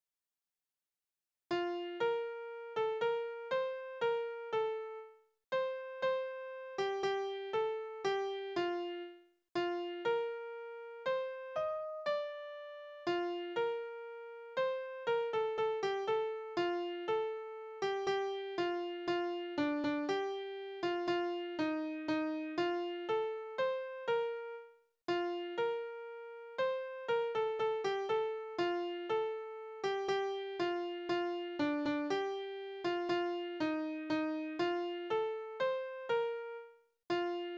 Tanzverse: Walzer
Tonart: B-Dur
Taktart: 4/4
Tonumfang: Oktave
Besetzung: vokal